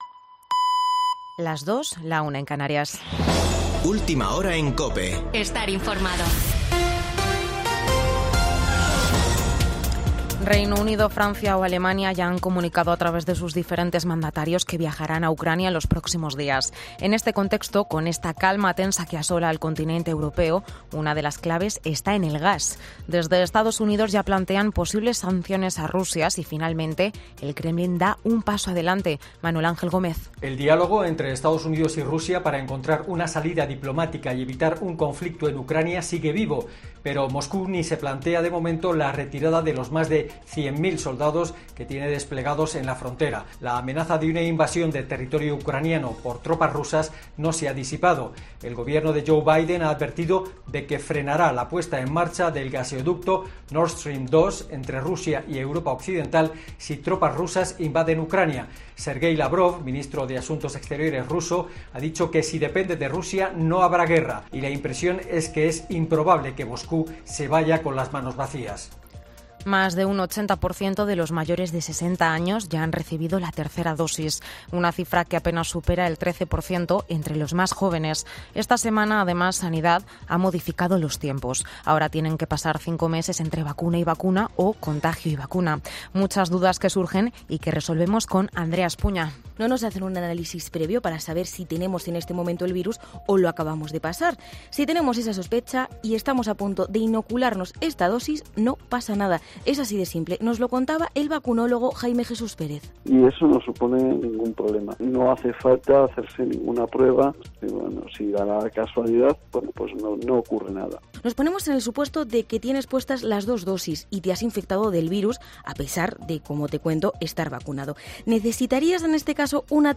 Boletín de noticias COPE del 30 de enero de 2022 a las 02.00 horas